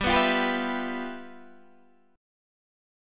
G13 chord